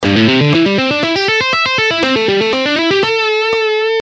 This hybrid picking style combines the best of alternate picking and sweep picking, enabling you to play scales, arpeggios, and complex runs with smoothness and precision.
economy-picking-lesson.gpx-2.mp3